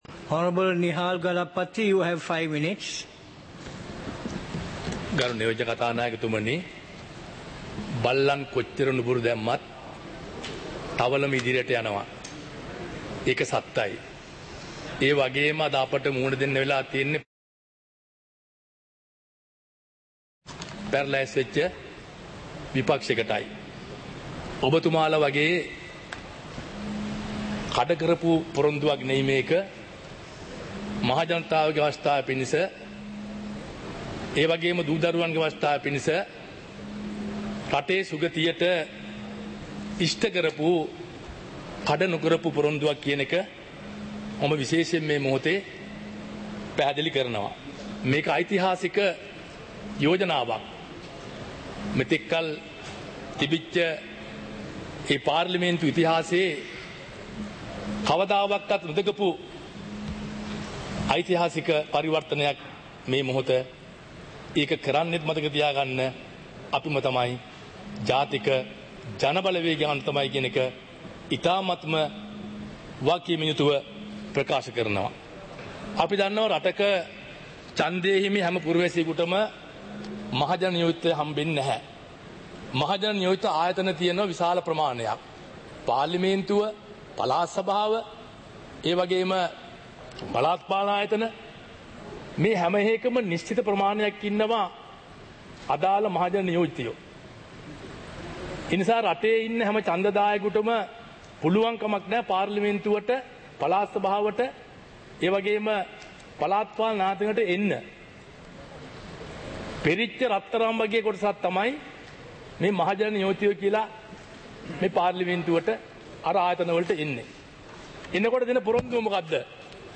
සභාවේ වැඩ කටයුතු (2026-02-17)